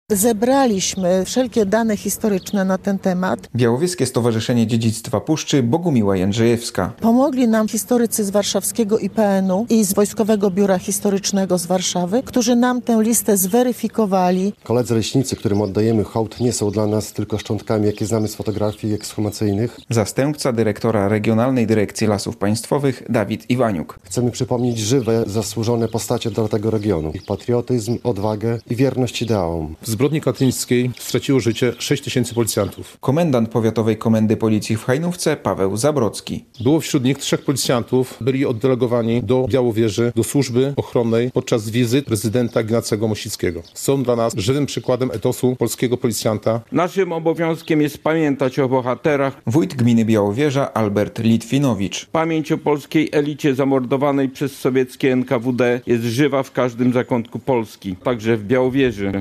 Odsłonięcie pomnika Pamięci Ofiar Zbrodni Katyńskiej w Białowieży - relacja